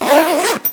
action_open_backpack_2.ogg